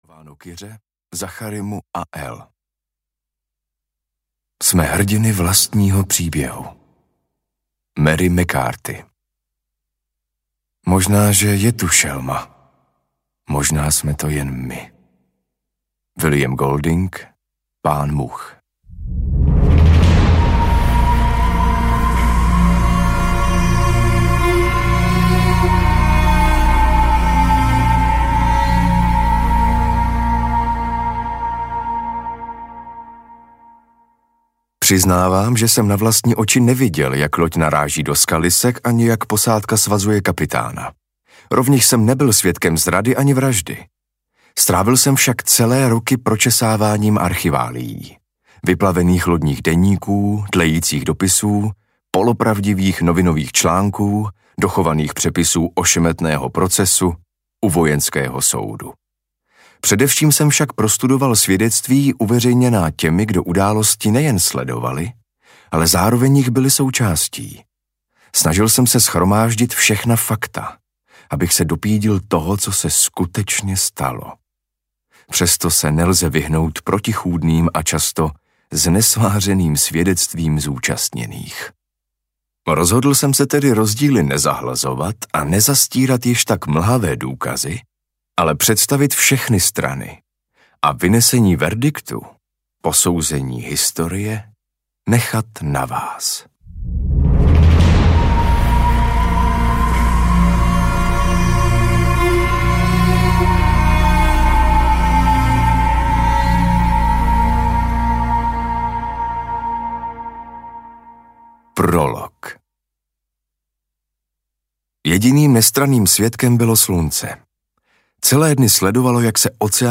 Wager audiokniha
Ukázka z knihy
wager-audiokniha